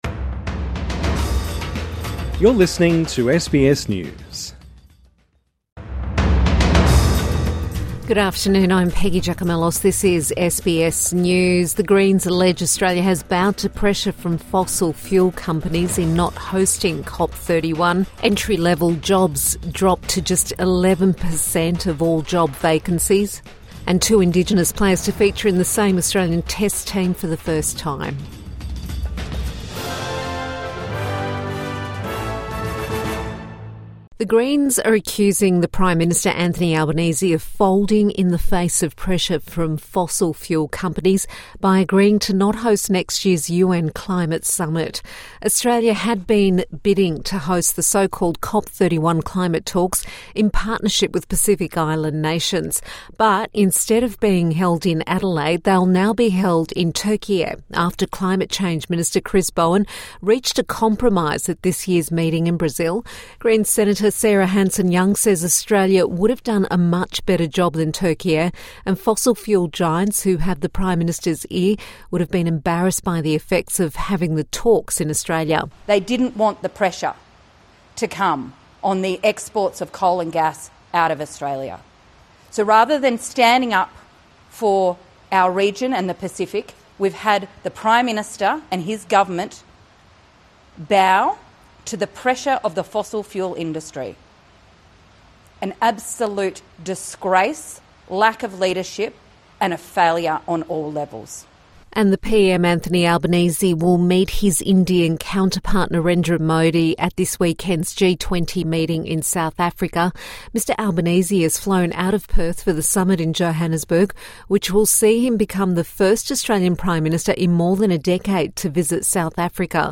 The Greens criticise government over climate summit | Evening News Bulletin 20 December 2025